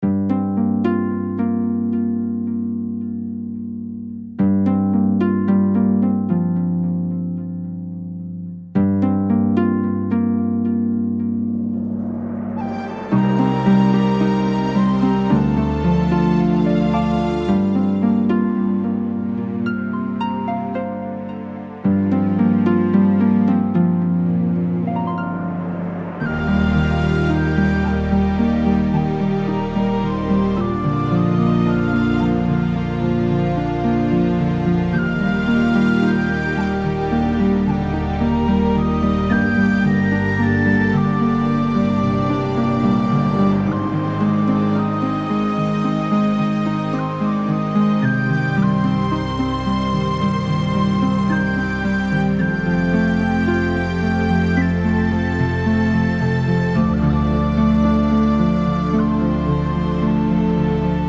Мелодия, играющая в магазинах Джиграна.